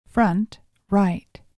audio-channel-front-right.mp3